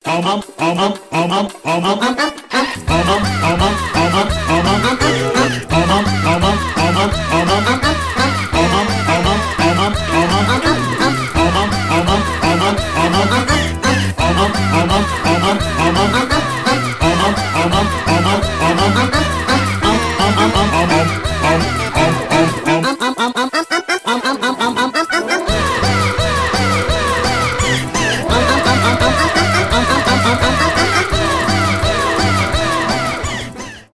веселые
быстрые